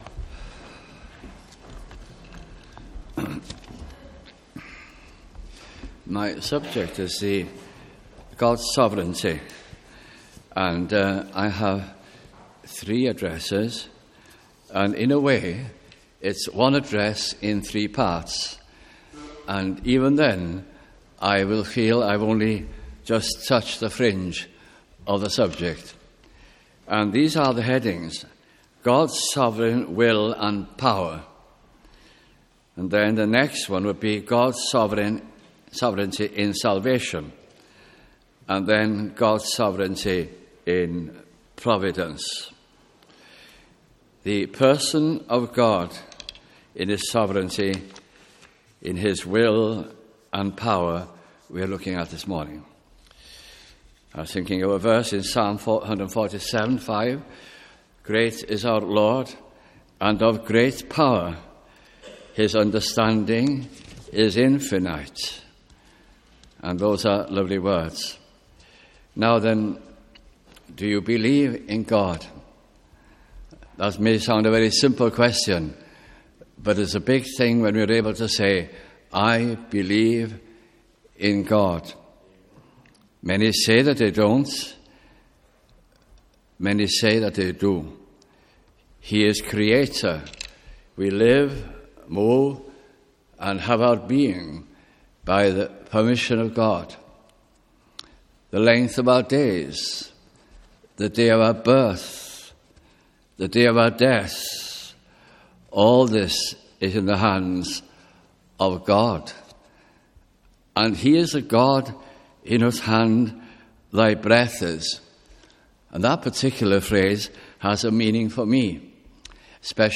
» Sovereignty of God » Summer Conference Cardiff 2011